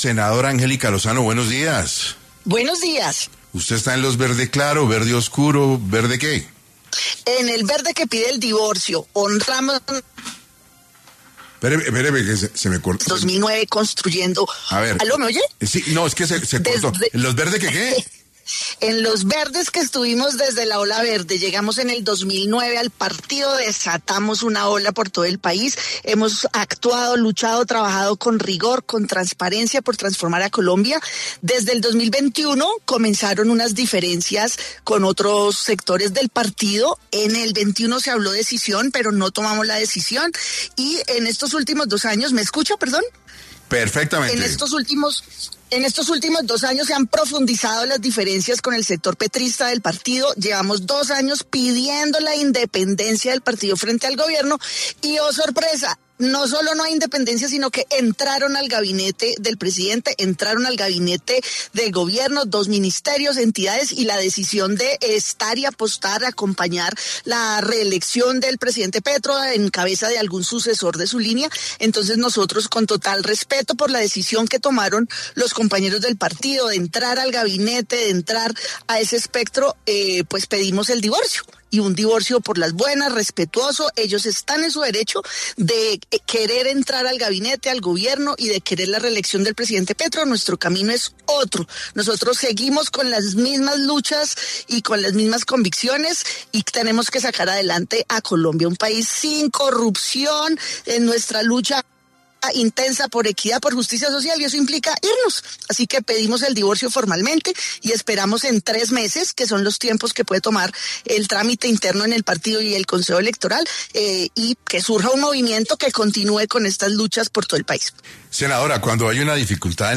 En 6AM de Caracol Radio estuvo la senadora Angélica Lozano, para hablar sobre qué viene para la Alianza Verde tras la separación de sus integrantes.